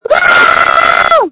JUST SCREAM! Screams from December 1, 2020
• When you call, we record you making sounds. Hopefully screaming.